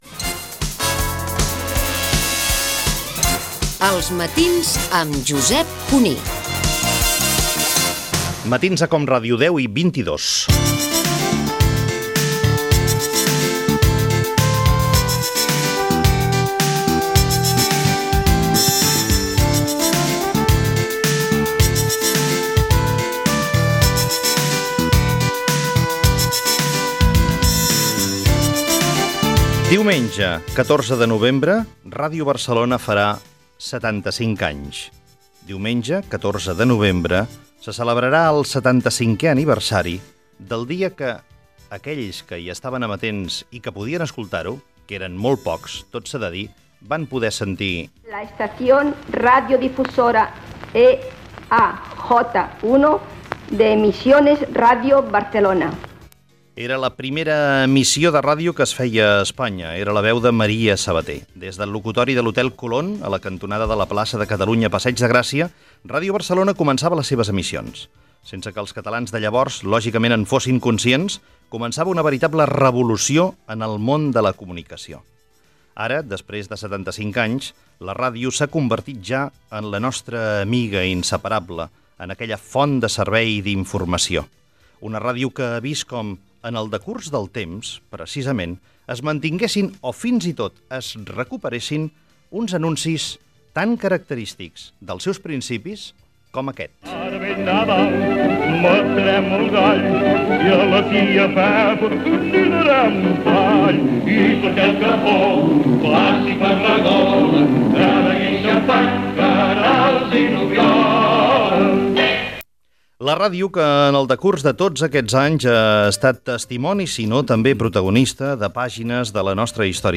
Indicatiu del programa, hora. Espai dedicat al 75è aniversari de Ràdio Barcelona.
Anuncis publicitaris anrics. Conversa amb el periodista Carles Sentís que va ser director de l'emissora.
Info-entreteniment